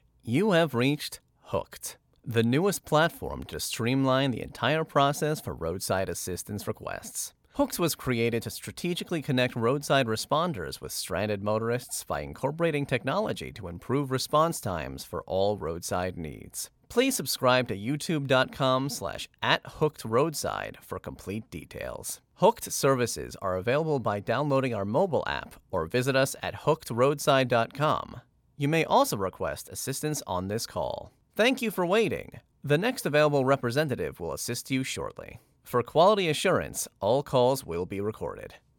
Inglés (Americano)
Natural, Amable, Cálida
Telefonía